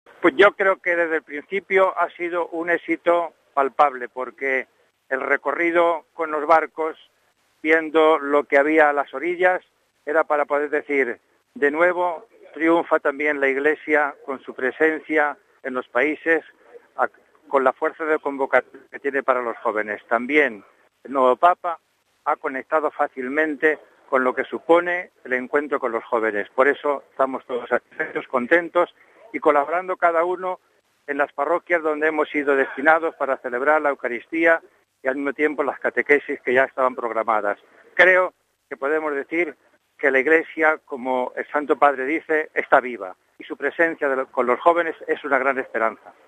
De éxito palpable ha calificado el Arzobispo de Valencia, monseñor Agustín García Gasco, la XX Jornada Mundial de la Juventud, que está llegando a su fin: RealAudio